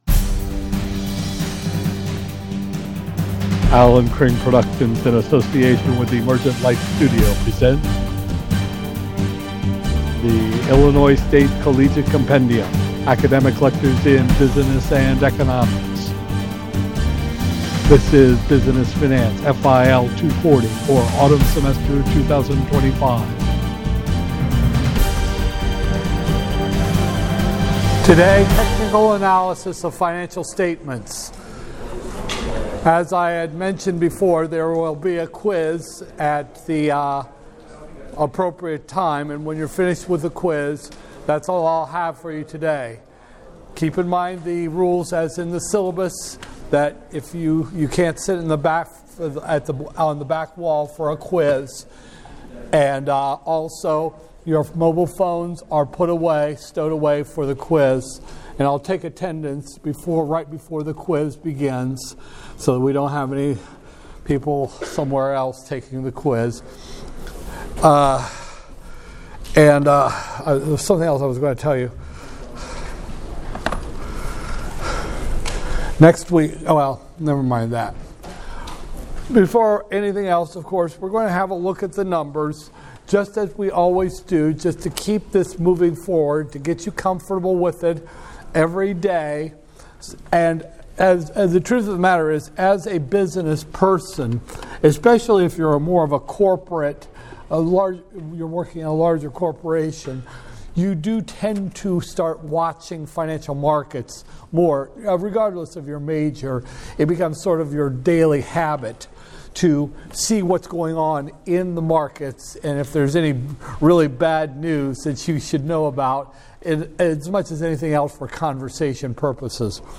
Business Finance, FIL 240-001, Spring 2025, Lecture 7